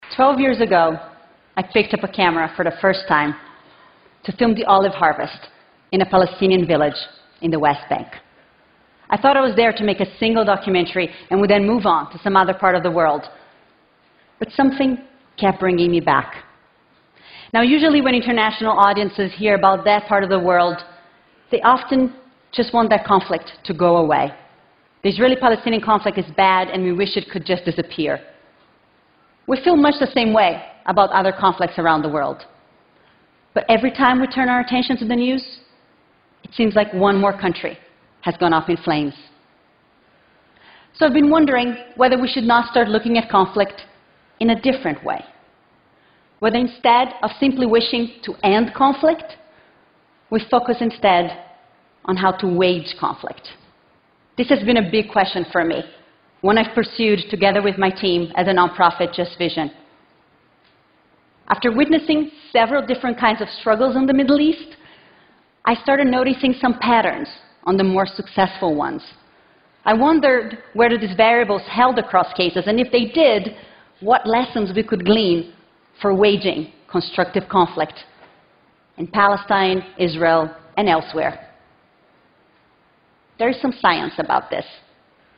TED演讲:女性是怎样发起非暴力冲突的(1) 听力文件下载—在线英语听力室